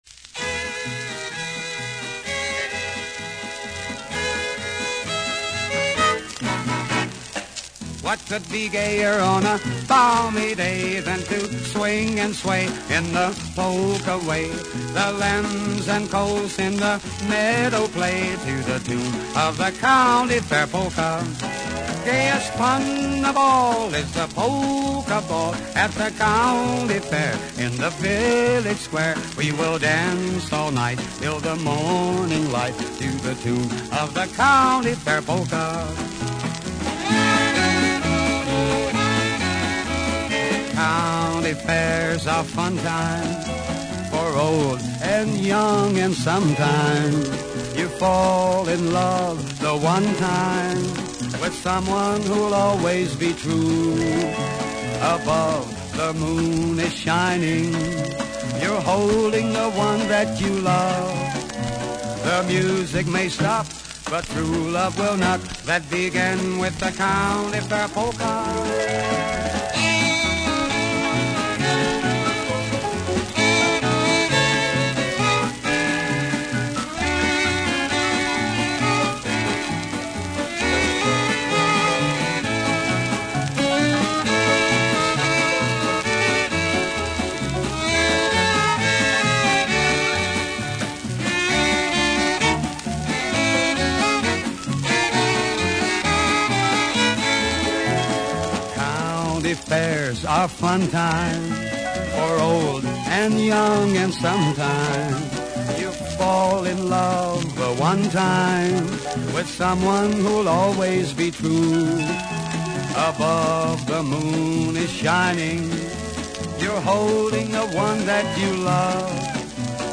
Polka
Waltz
Schottische
Commentary
Laendler